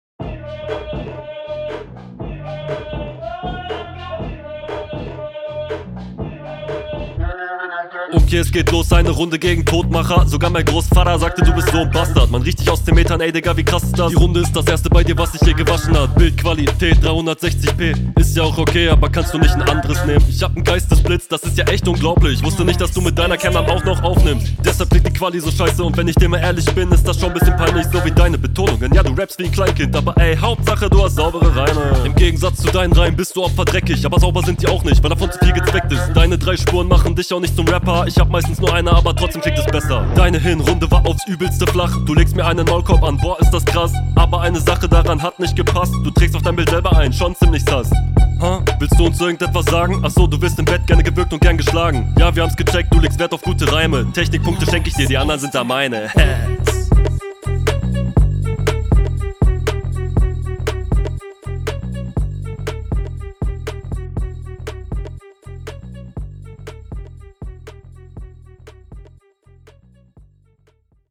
Erstmal, der Beat ist mieees geil, feier ich komplett.
Booooh Flow ballert!